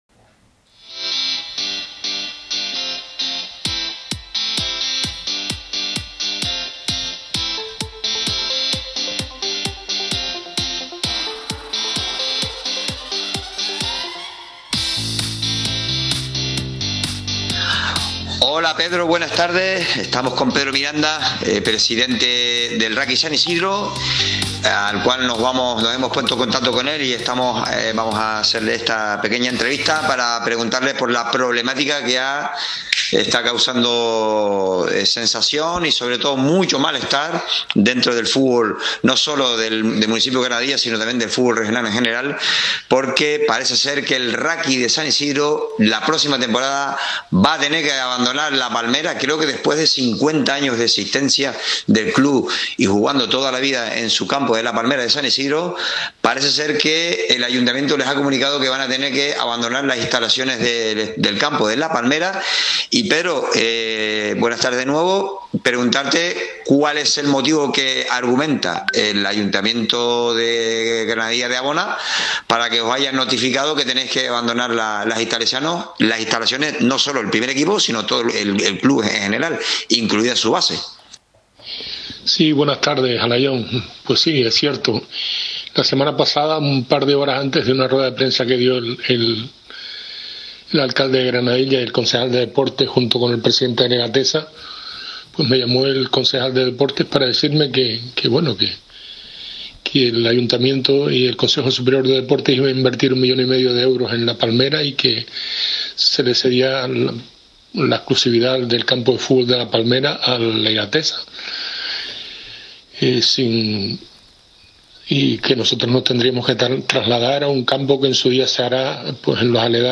y le ha concedido la siguiente entrevista